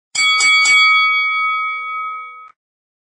Campana de Box